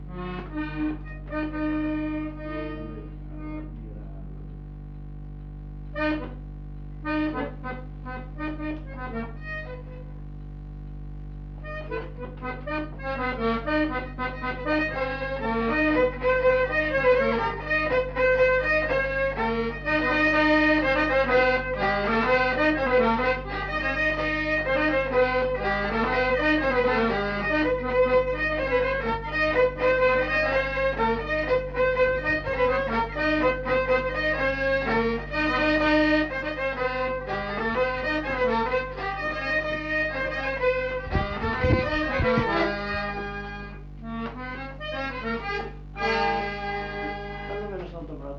Répertoire de danses des Petites-Landes interprété au violon et à l'accordéon chromatique
Rondeau